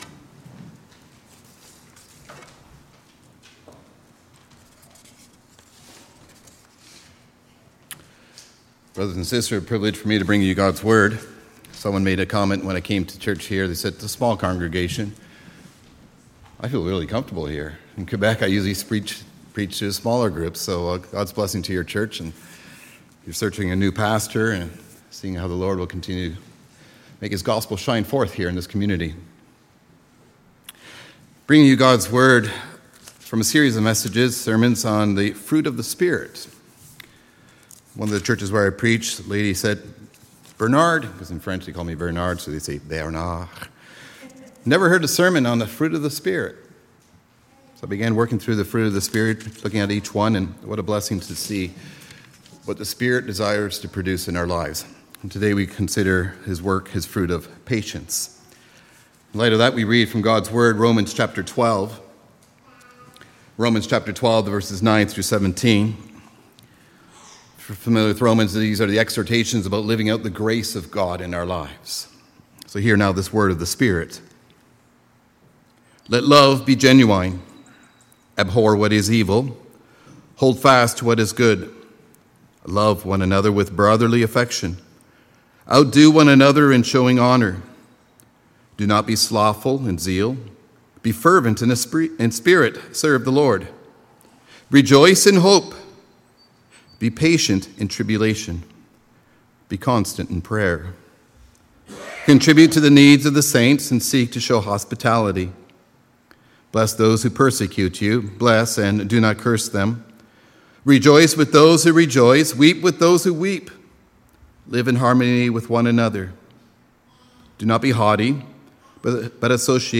Sermon “The Fruit of the Spirit